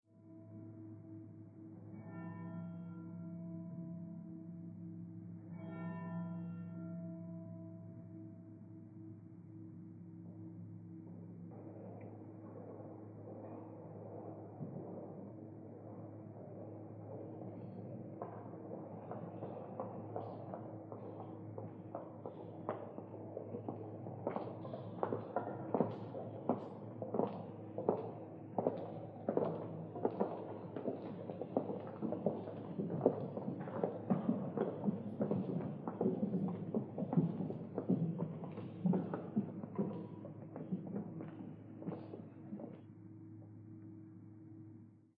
Pre-Evensong Tolling Bell and Processional Clip-clop by the Priory Singers of Belfast at Truro Cathedral